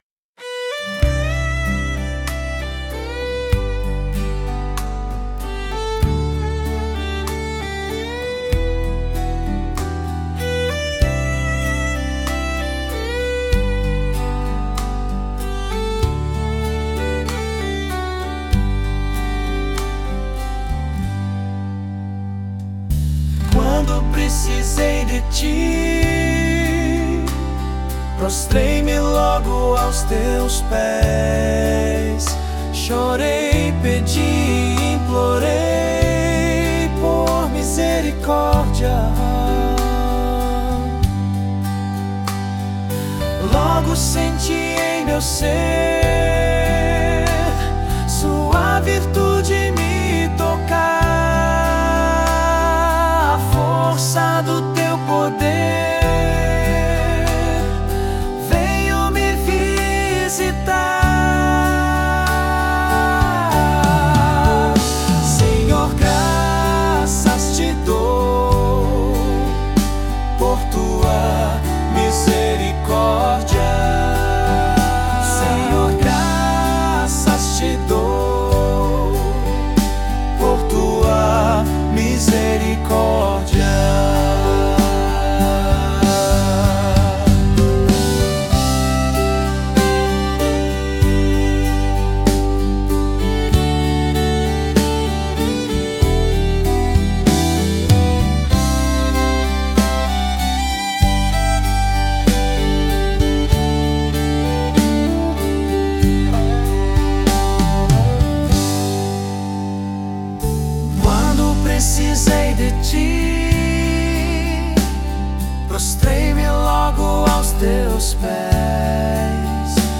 [Vocal Masculino]